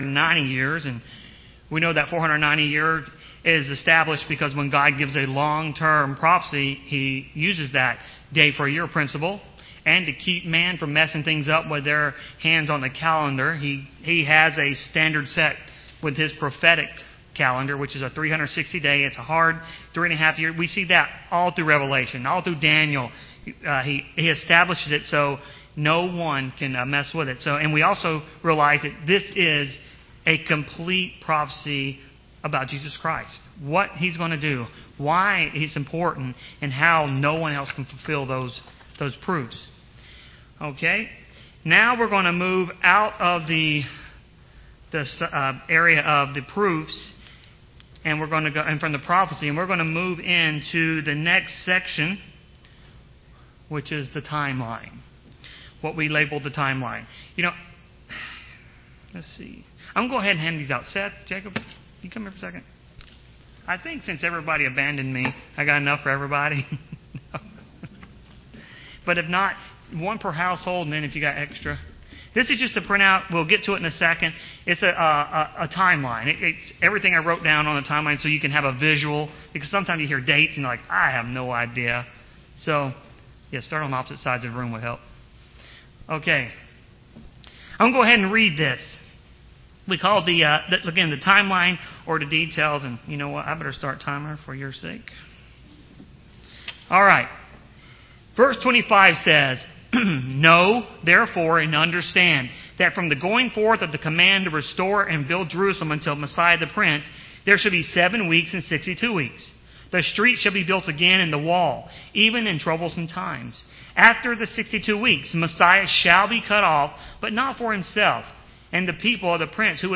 Sermons
Given in Rome, GA